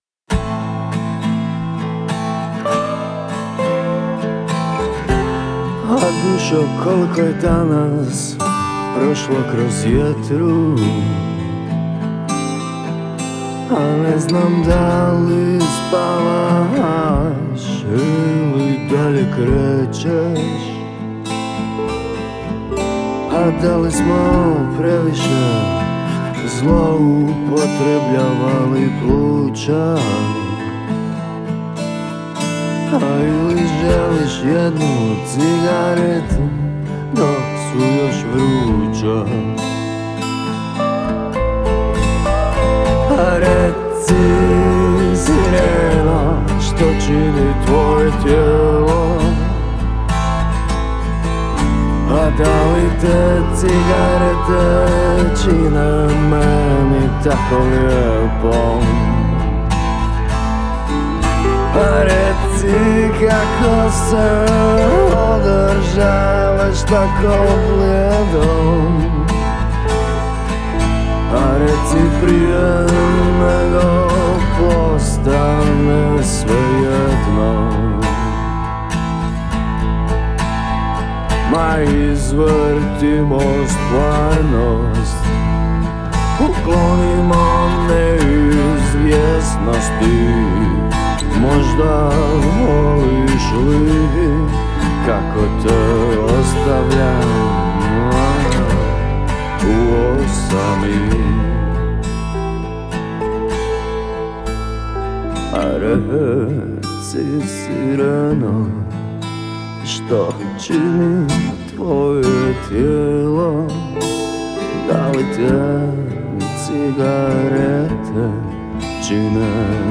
stvarajući alter rock sentiš bez bubnja.